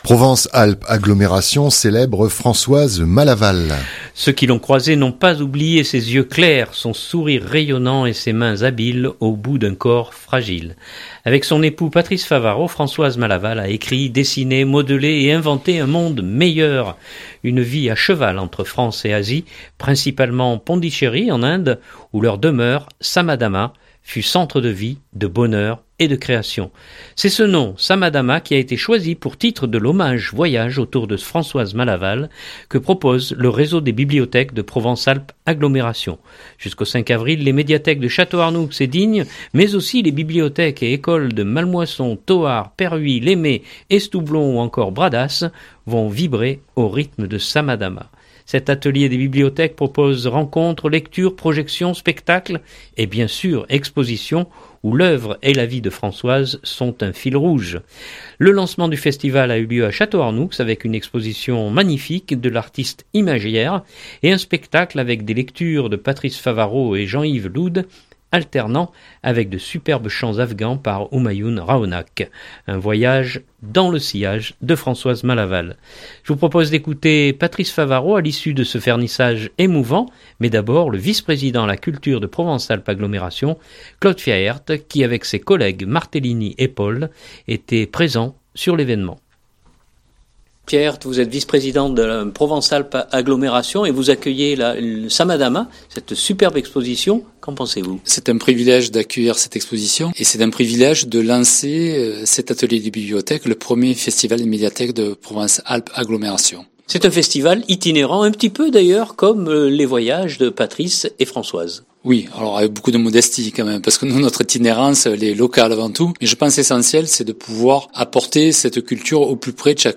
2019-02-27-reportage-Provence-Alpes-Agglomeration.mp3 (2.97 Mo)